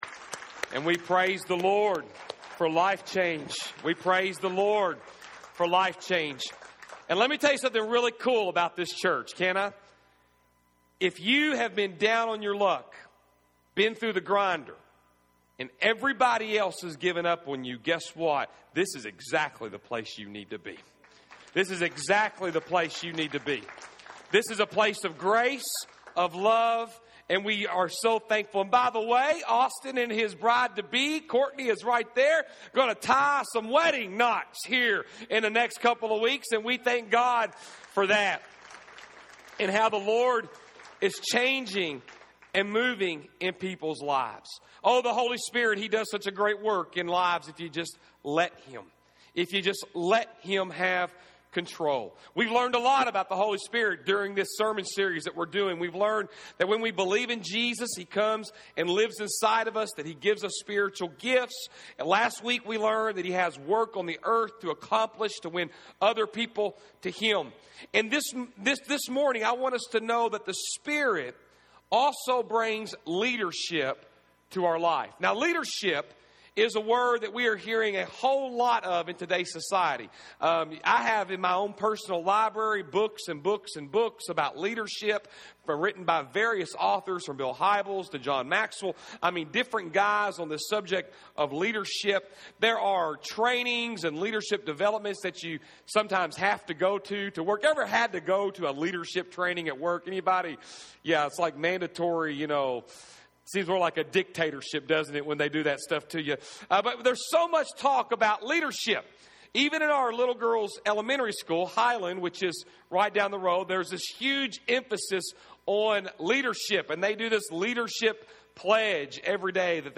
October 11, 2015 Movement: “The Spirit’s Leadership” Service Type: Sunday AM Fourth message in the series "Movement".